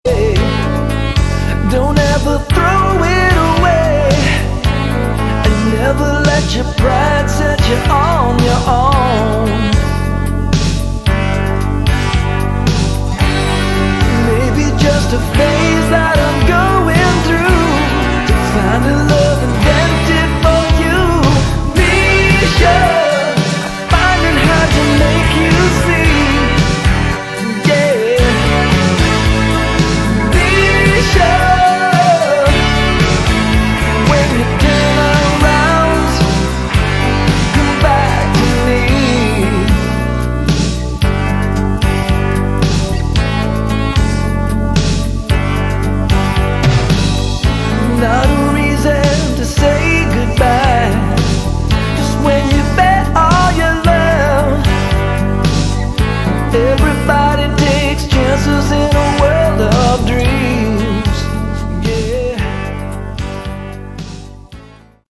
Category: AOR / Melodic Rock
Guitar, Lead Vocals
Bass, Vocals
Drums
Keyboards